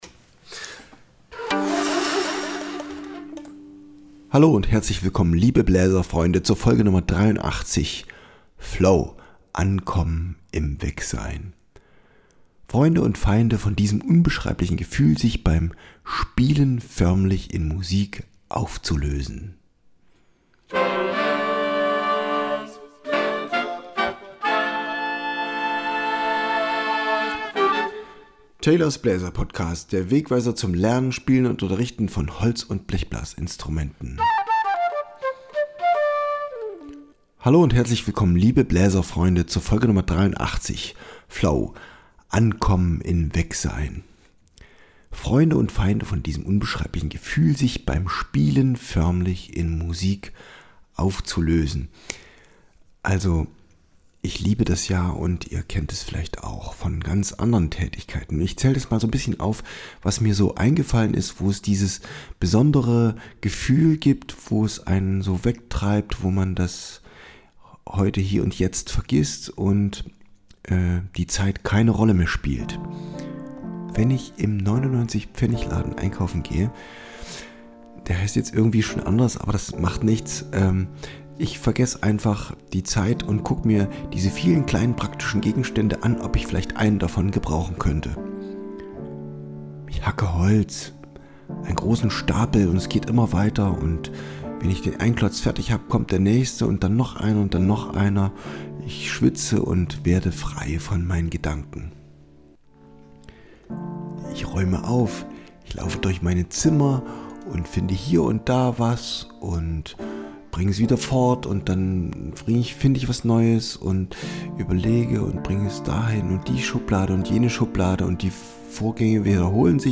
Bonusmusik: J.S.Bach aus Triosonate A-Dur mit obligatem Bass „Siciliano“. Orgel (Studiologic NUMACompact2x) und Holzquerflöte